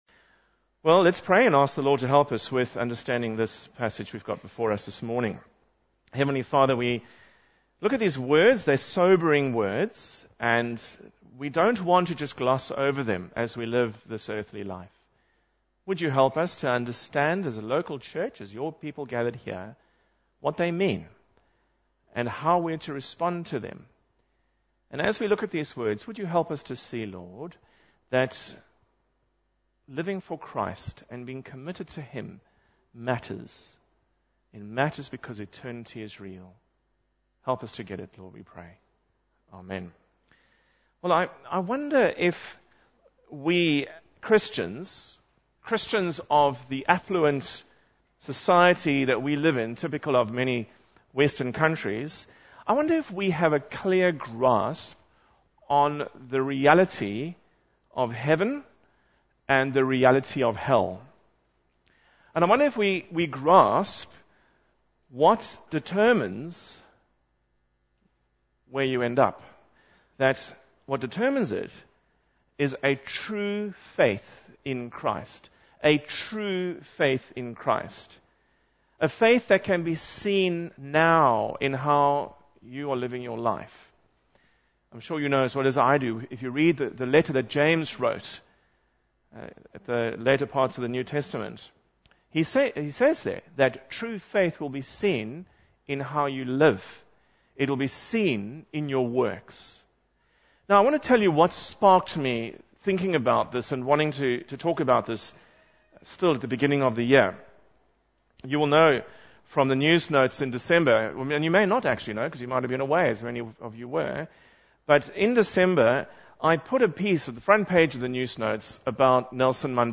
Luke 9:23-26 Service Type: Morning Service Bible Text